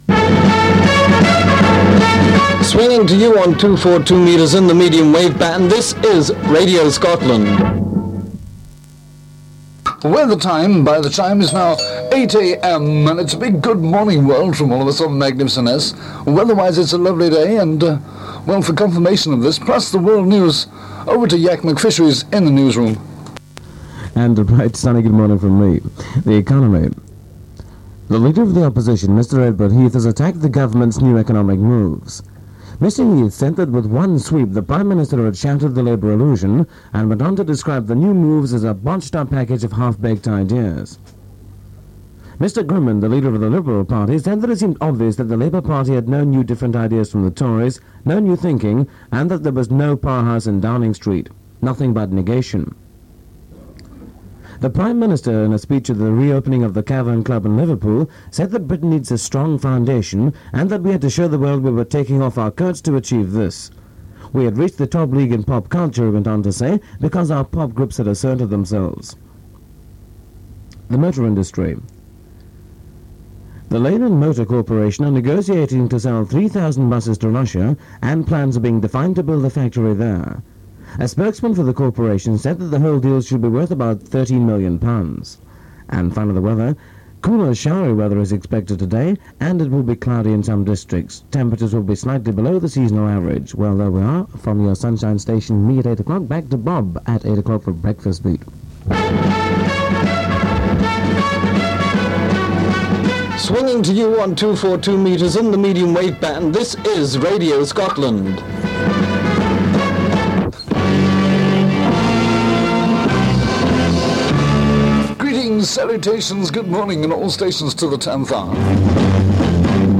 The recordings were made on a couple of Sundays in July 1966, using an Alba receiver and a Ferrograph 422U tape recorder.
We start with a recording of Radio Scotland opening up for the day.
The theme tune is Revenge by The Ray McVay Sound. We think it is Stuart Henry on the Silexine advert.